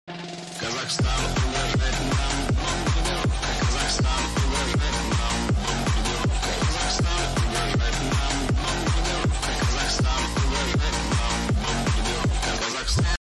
Песня ремикс (13 сек)